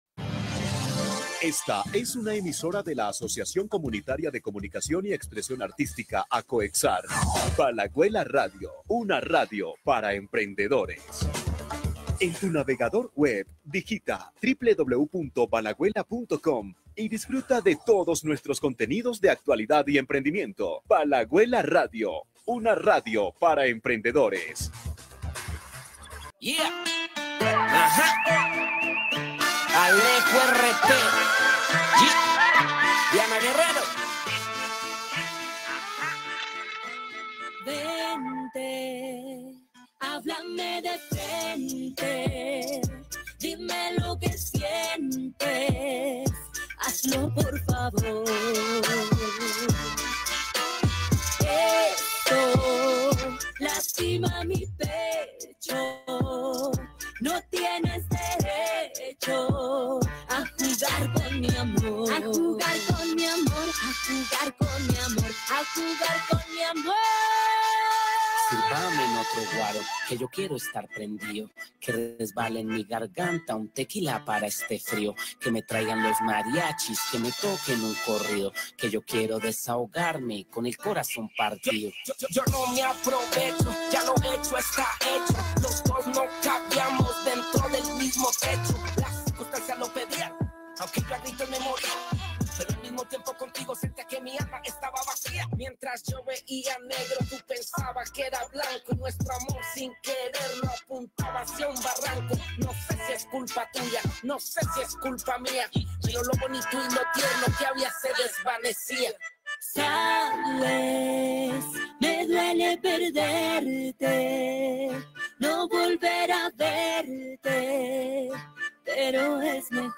Localidad ENE es un espacio de dialogo y convivencia con agentes locales emitido en vivo este y todos los sábados a partir de las 8 de la mañana y con repetición miércoles desde las 7 pm desde Valaguela Radio